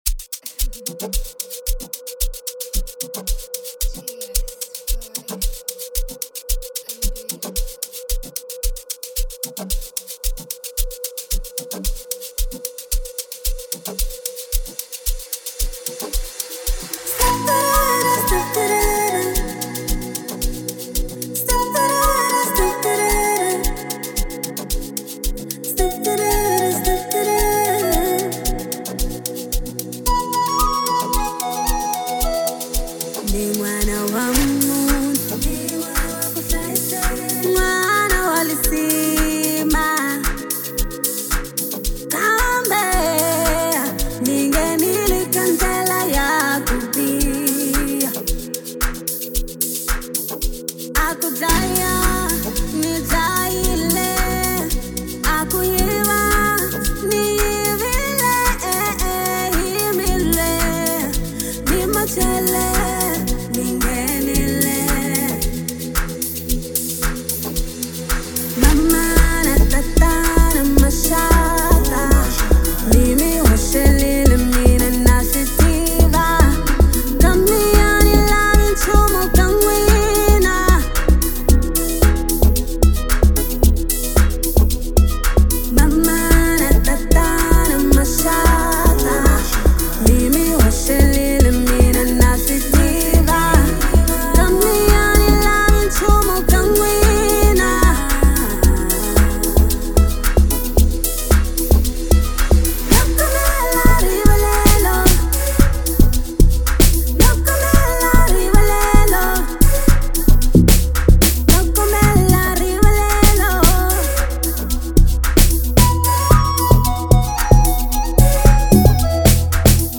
é um tema dançante